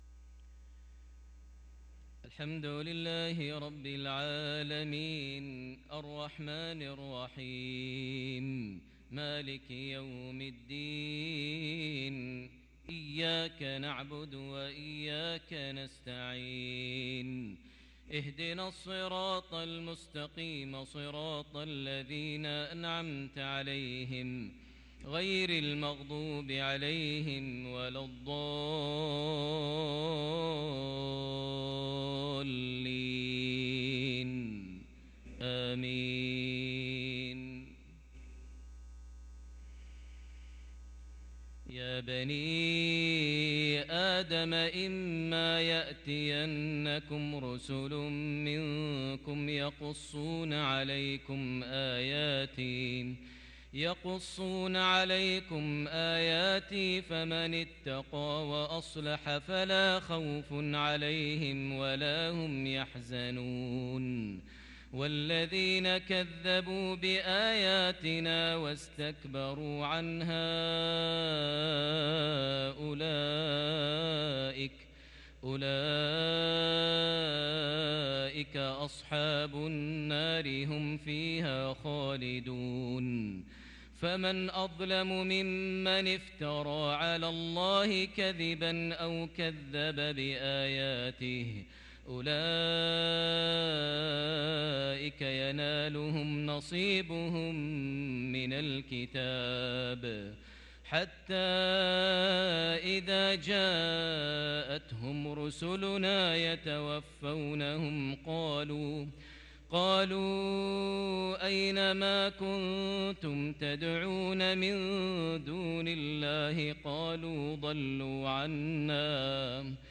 صلاة العشاء للقارئ ماهر المعيقلي 14 صفر 1444 هـ
تِلَاوَات الْحَرَمَيْن .